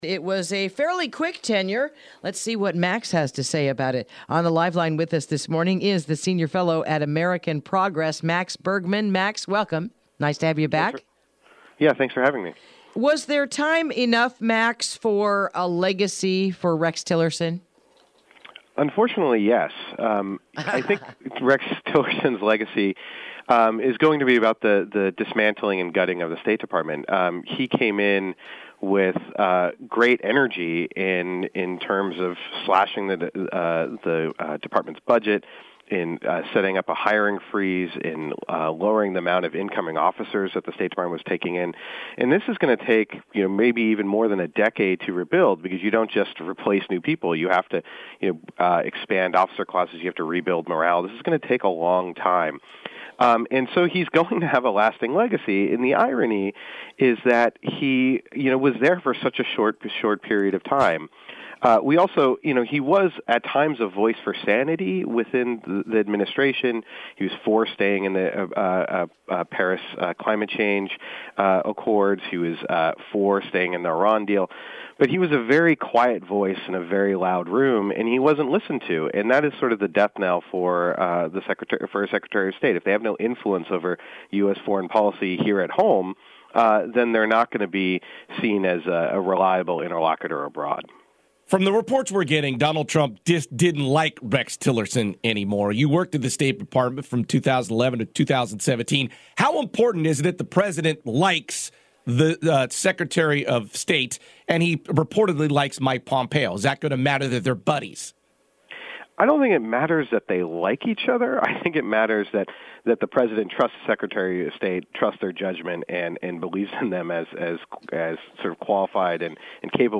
Interview: The Future of the State Department in the Post-Tillerson Era